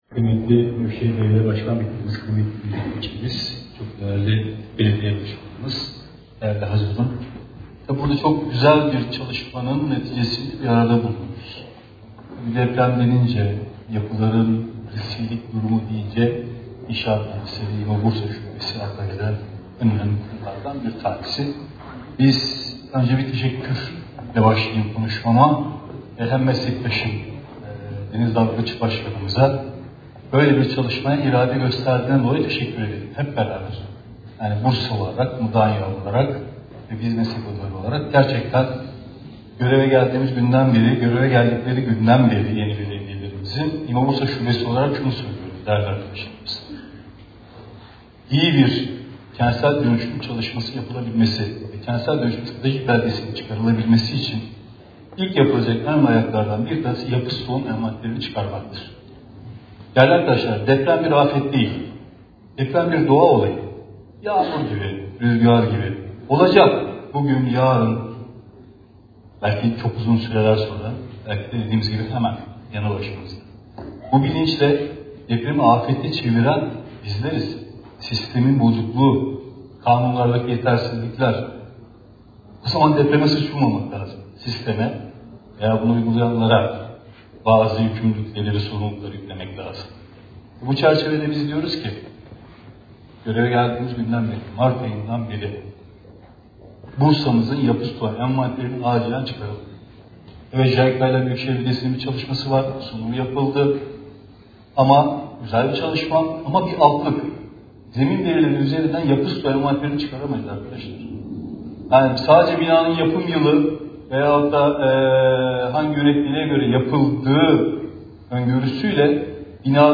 Konuşması